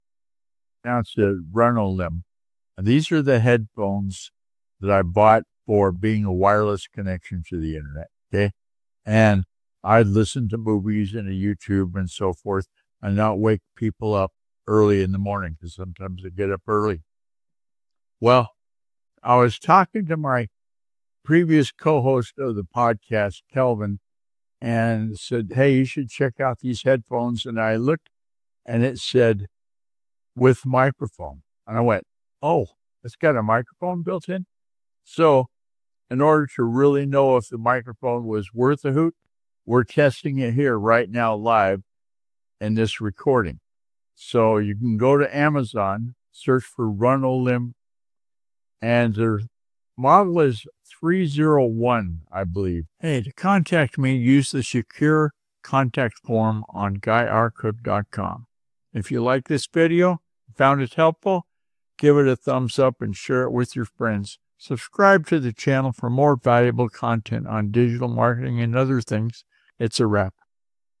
The video includes a recommendation for viewers to check out the headphones on Amazon and encourages engagement through likes, shares, and subscriptions for more digital marketing content. It’s a straightforward review with a focus on both product features and community interaction.